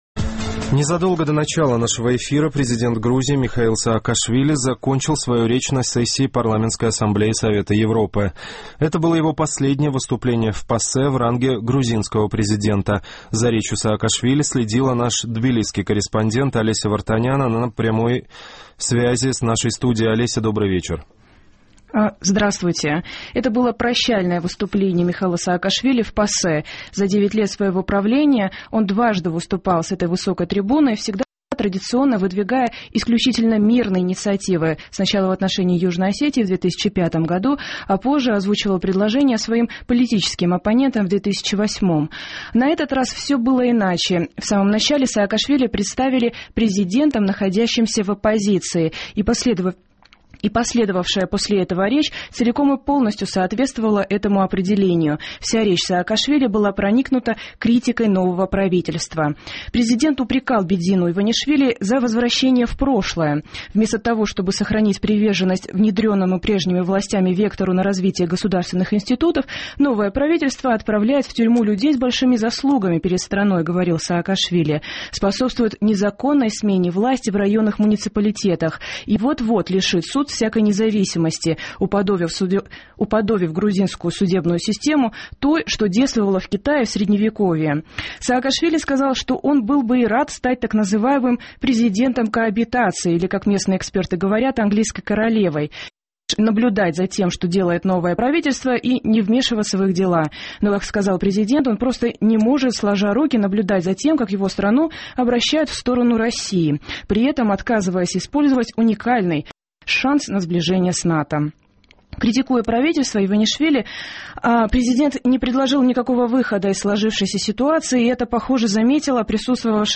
Это было прощальным выступлением Михаила Саакашвили в ПАСЕ. За девять лет своего правления он дважды выступал с этой высокой трибуны, традиционно выдвигая исключительно мирные инициативы.